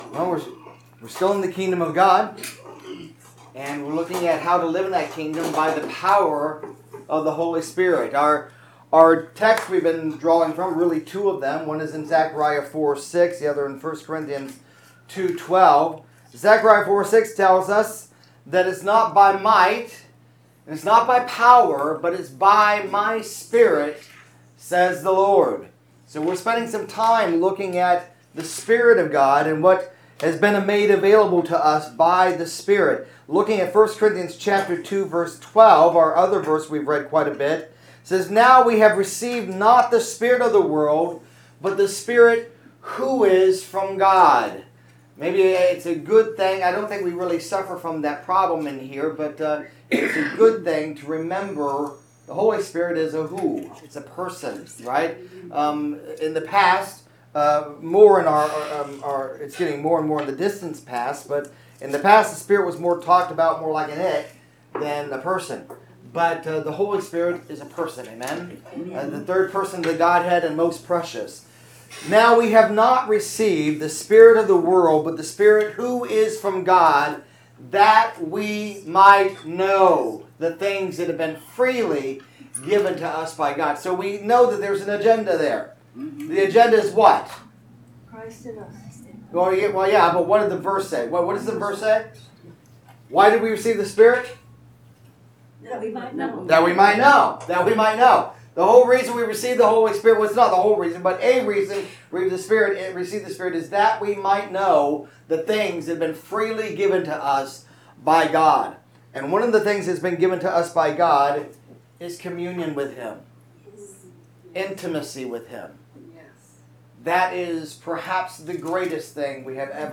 Message – Fellowship with God I